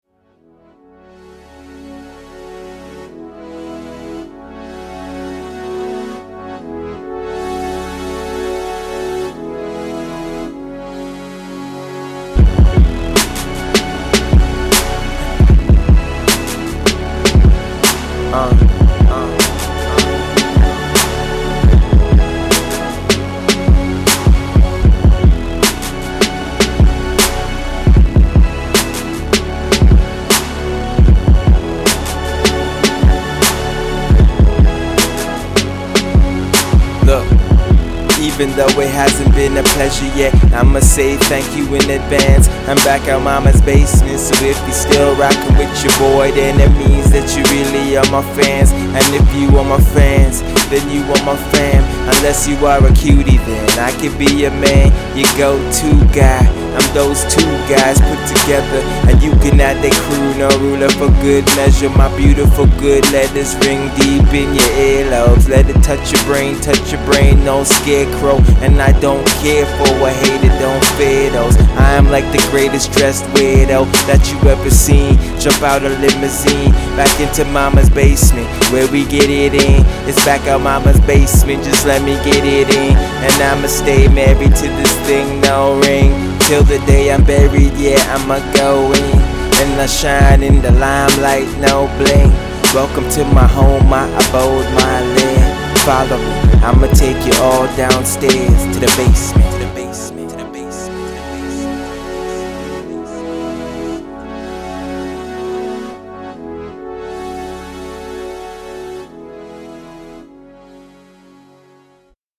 His flow sounds natural, unrehearsed with a raw edge.
mixtape